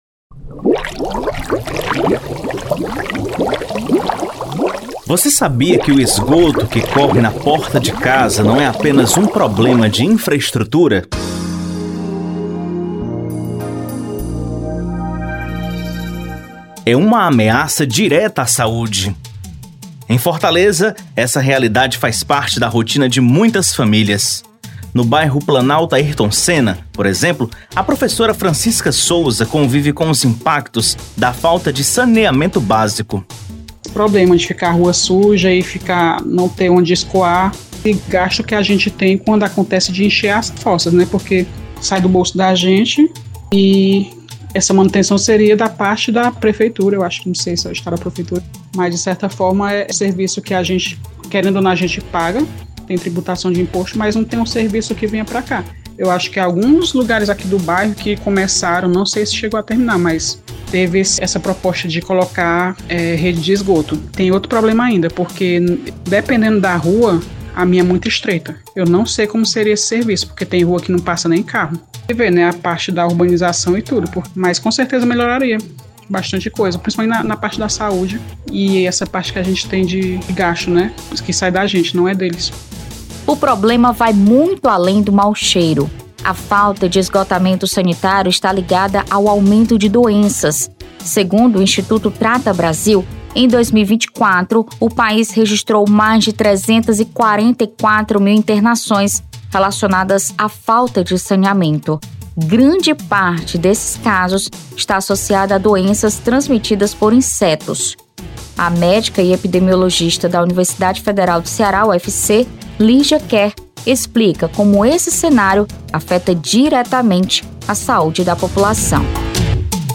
FM Dom Bosco exibe reportagem especial que aborda temática do saneamento básico